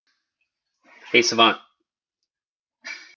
heysavant / wake-word